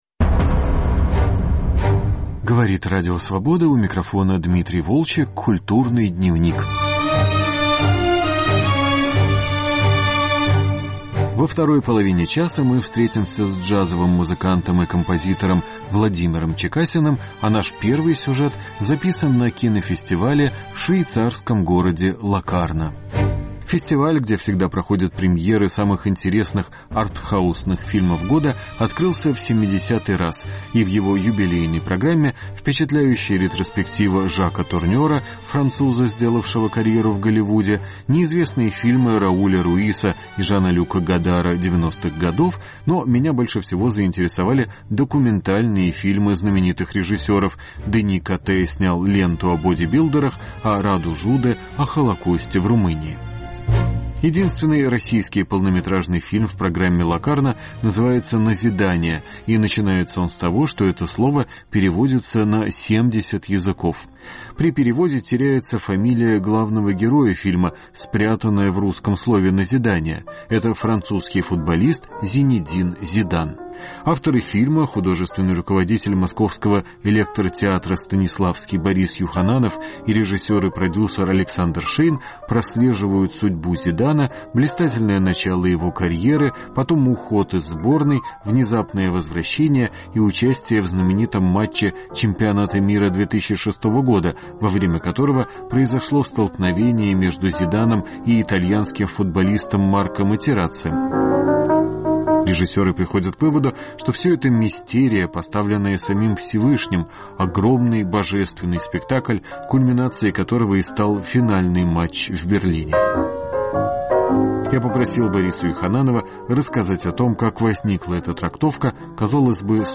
Кинофестиваль в Локарно +++ Разговор с джазовым музыкантом Владимиром Чекасиным